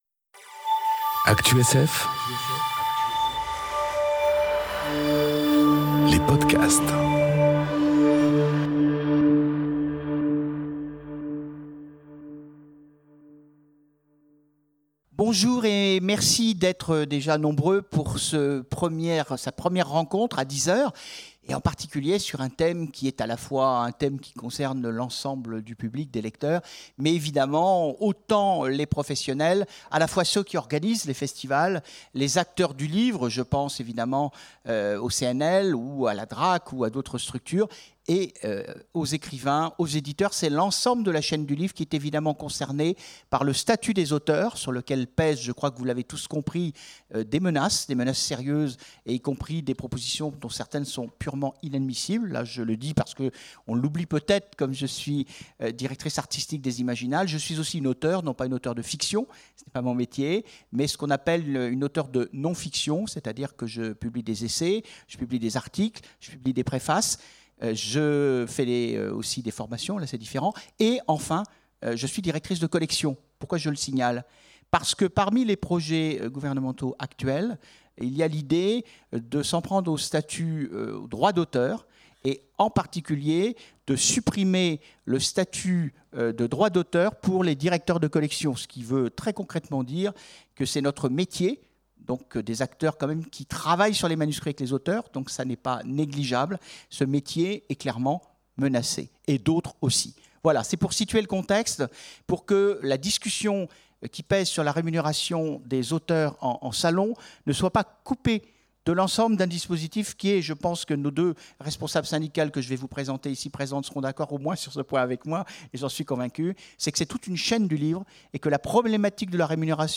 Conférence Payer les auteurs invités : jusqu'où aller ensemble ? enregistrée aux Imaginales 2018